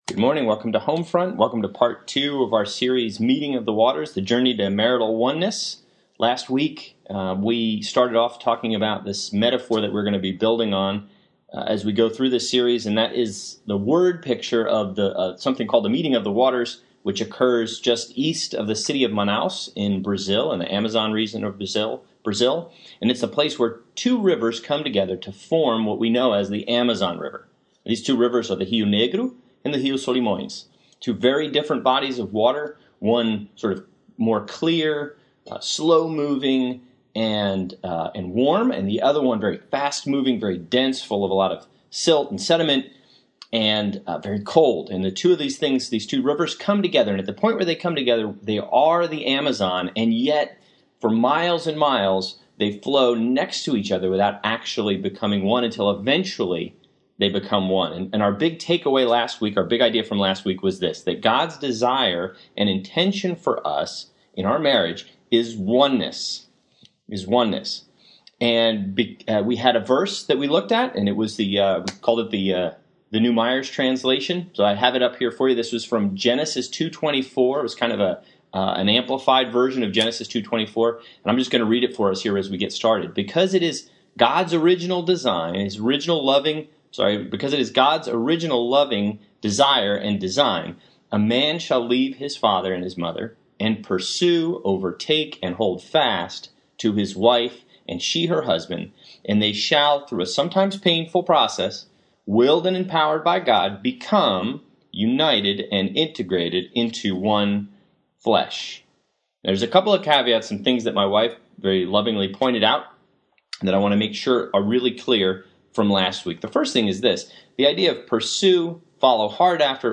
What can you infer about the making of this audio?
A series on the journey to marriage oneness, given at Reston Bible Church’s HomeFront couples fellowship.